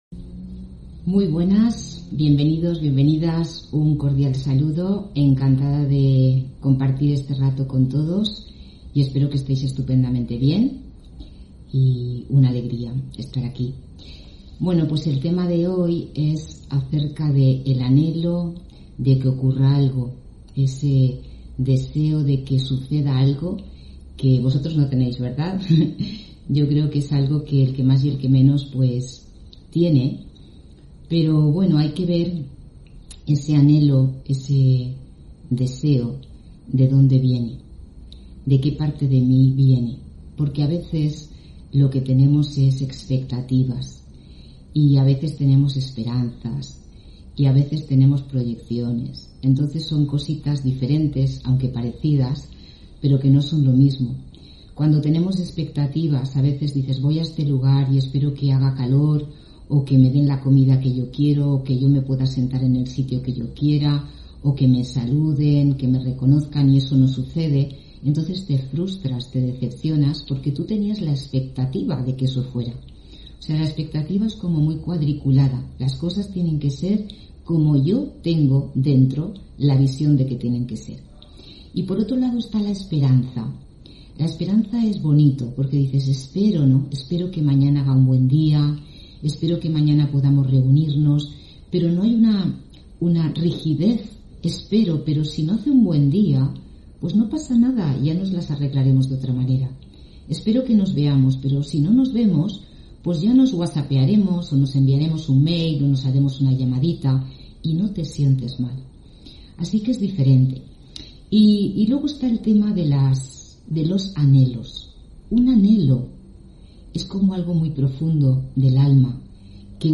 Meditación y conferencia: El anhelo de que ocurra algo (13 Noviembre 2021)